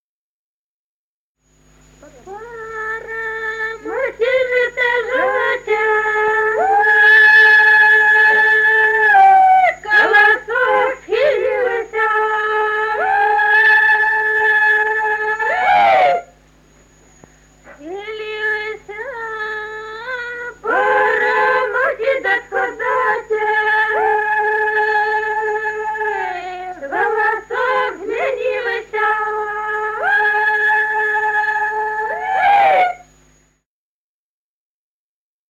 Народные песни Стародубского района «Пора, мати, жито жати», зажиночная.